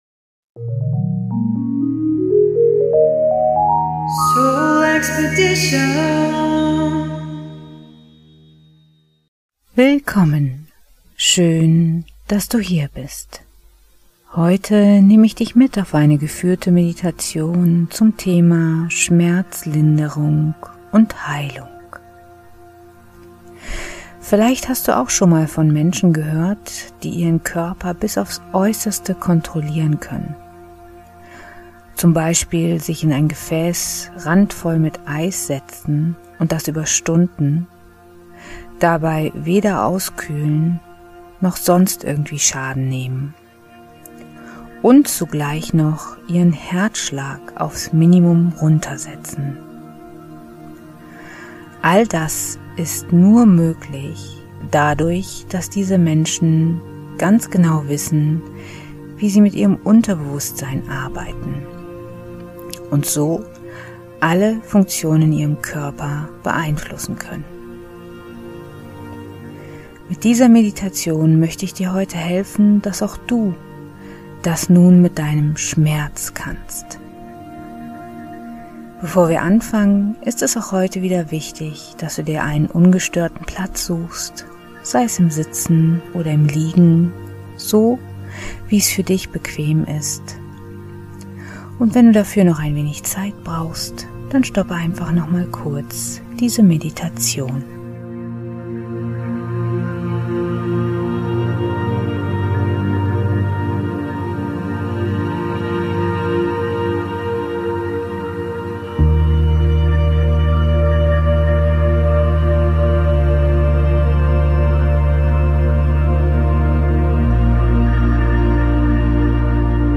Meditation/Hypnose Schmerzlinderung und Heilung ~ SoulExpedition Podcast